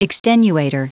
Prononciation du mot extenuator en anglais (fichier audio)